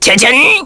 Lakrak-Vox_Skill1-1_kr.wav